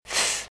fricative sourde
sons_Consonnes_Vitrine_f.mp3